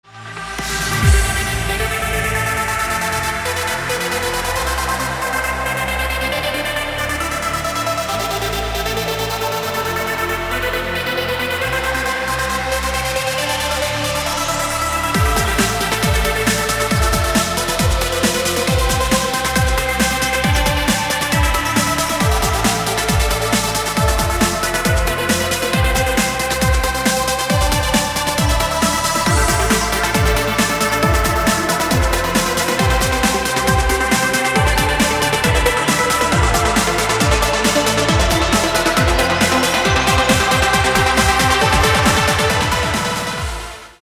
громкие
dance
Electronic
без слов
Trance
Стиль: транс